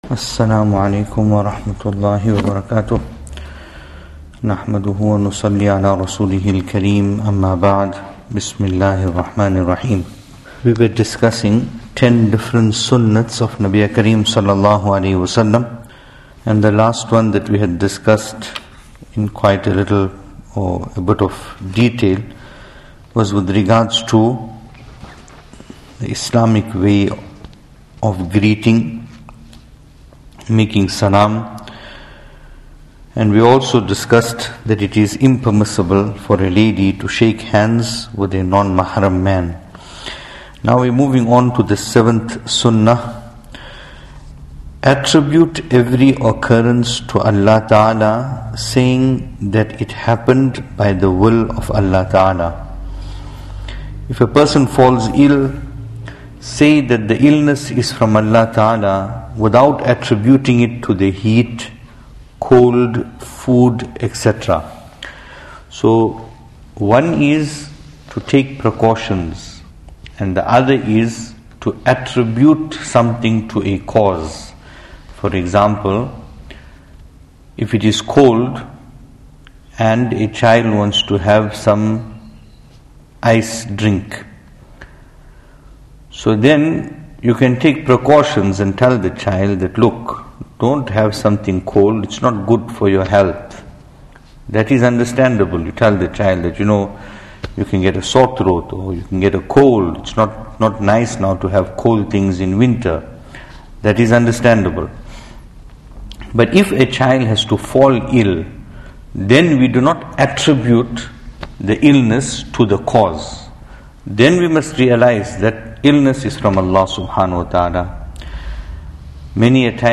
Venue: Pietermaritzburg | Series: Tohfa-e-Dulhan